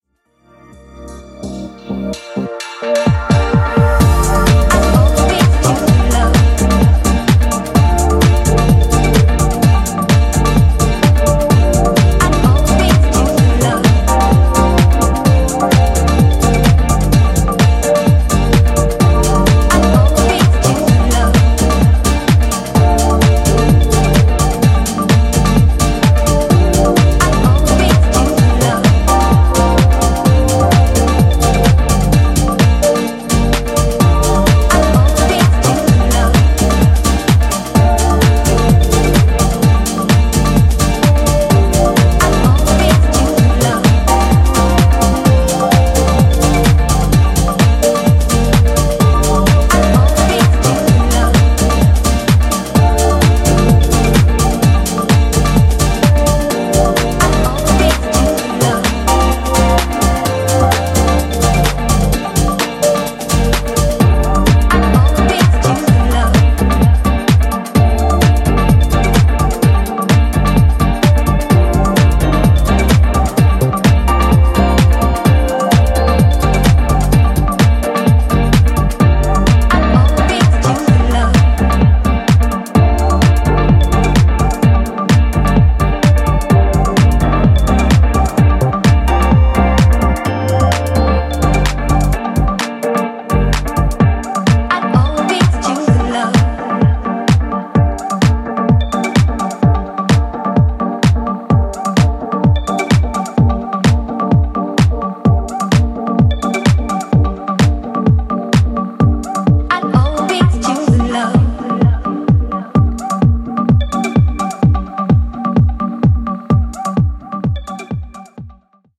ear candy and sparkling sounds
House